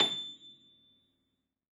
53h-pno25-A5.wav